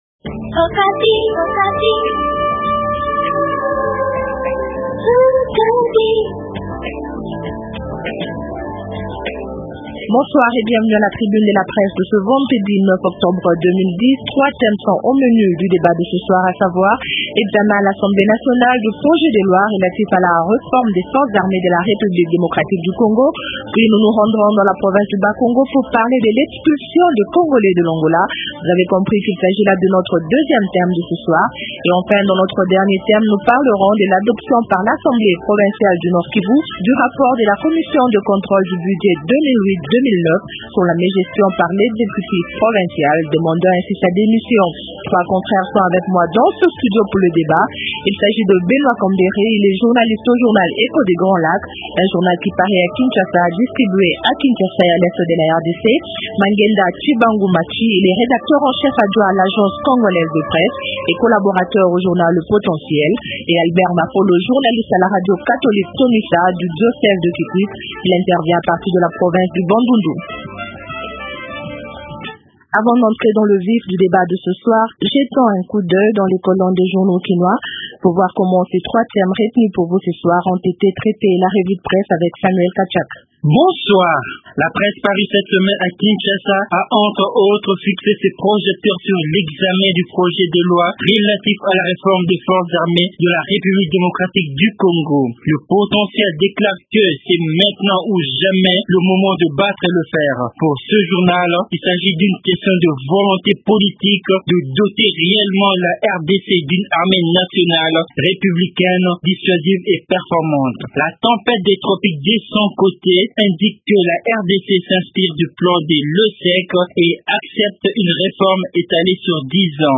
Trois thèmes dans la tribune de la presse de ce soir :